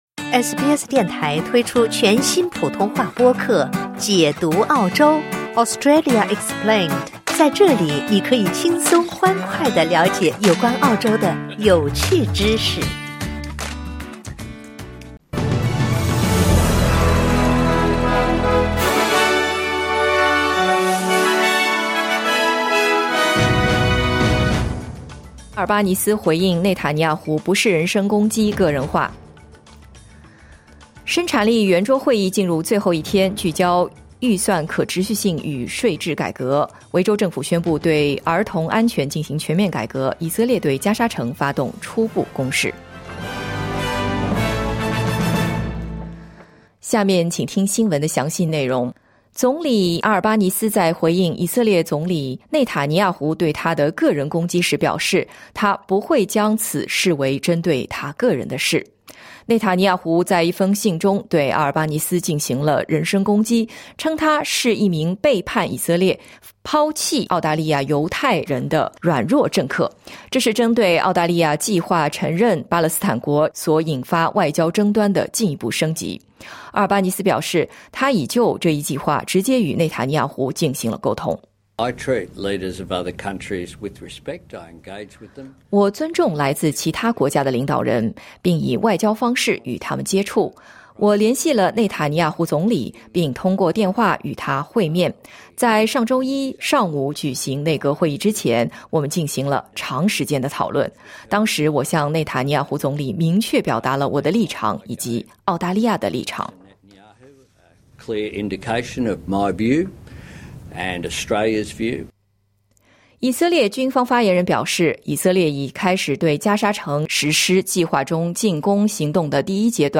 SBS早新闻 （2025年8月21日）